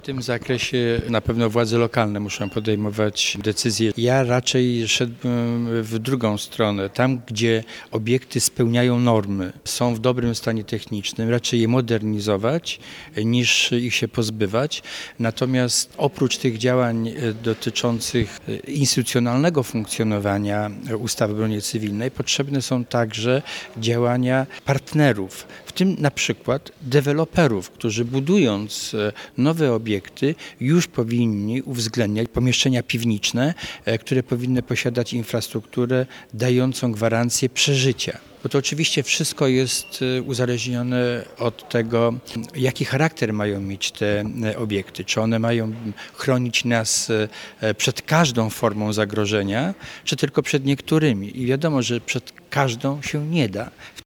Miasto sprzedało deweloperowi działkę, na której znajduje się jeden z najnowocześniejszych schronów zbudowanych po II wojnie światowej. Wiceminister obrony narodowej Stanisław Wziątek w rozmowie z Twoim Radiem podkreśla, że takie obiekty nie tylko powinny być zachowywane, ale również unowocześniane, aby mogły nadal pełnić swoją kluczową rolę w zapewnieniu bezpieczeństwa mieszkańców.